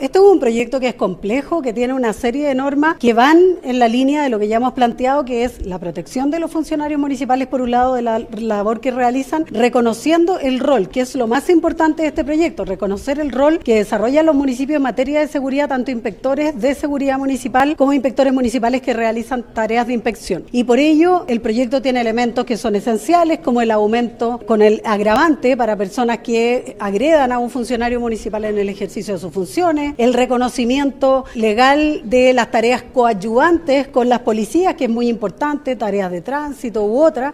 Por su parte, la subsecretaria de Prevención del Delito, Carolina Leitao, destacó que el proyecto fue aprobado por amplia mayoría en general y enfatizó que “este es un proyecto complejo que busca reconocer y fortalecer el rol de los municipios en materia de seguridad”.